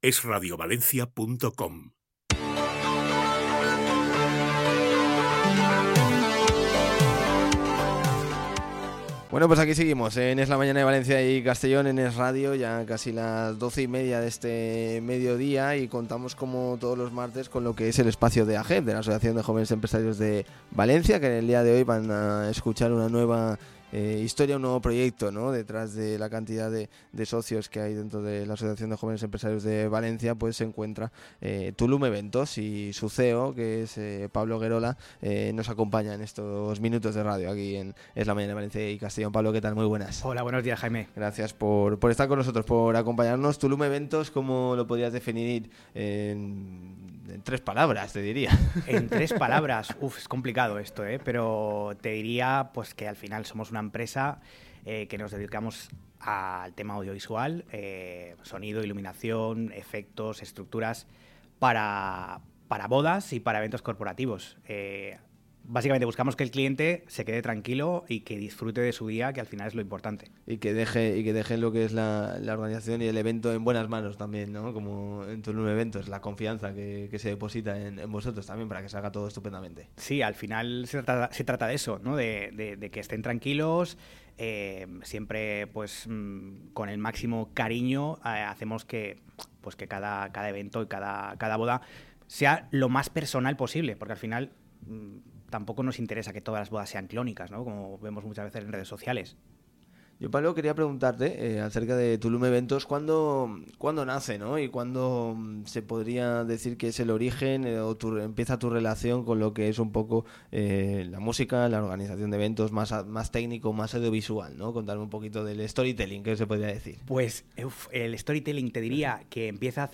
Entrevista completa: